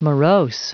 Prononciation du mot morose en anglais (fichier audio)
Prononciation du mot : morose